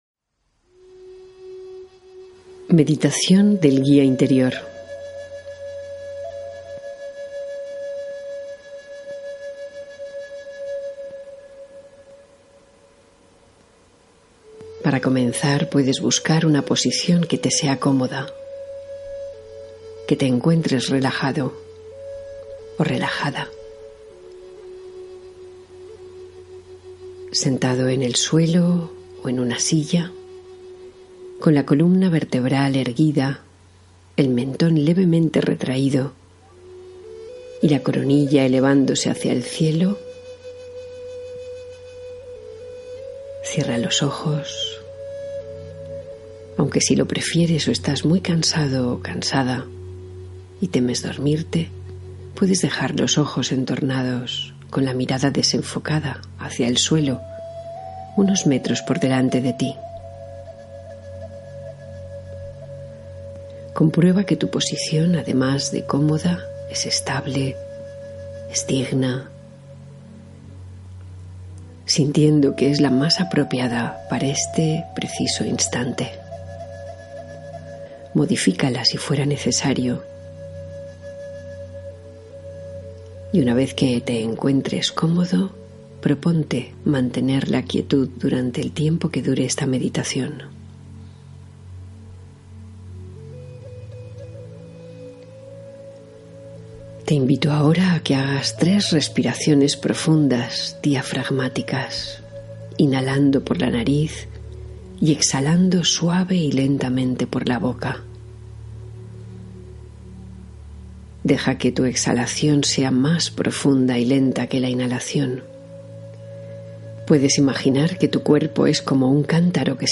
El Guía Interior: Meditación Guiada de Conexión Profunda